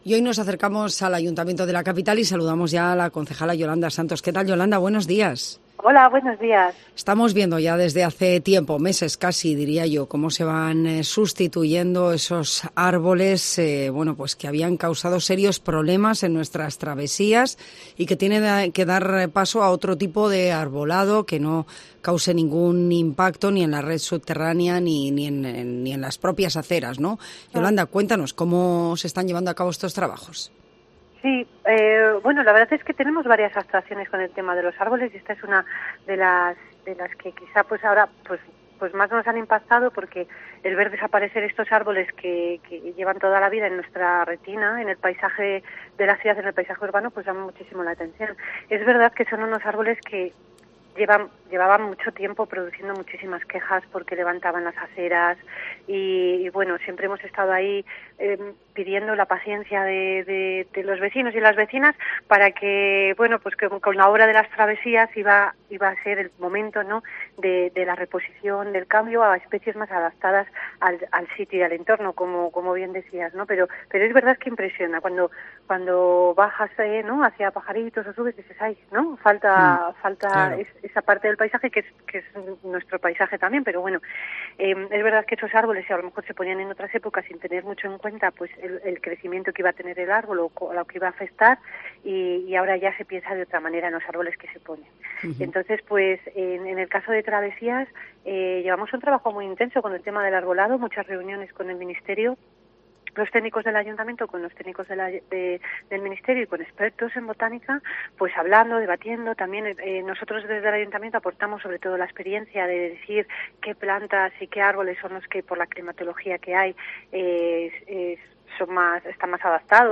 Yolanda Santos, concejala del Ayuntamiento de Soria, explica en COPE los trabajos de reposición del arbolado de la ciudad asociados a las obras de remodelación de las travesías.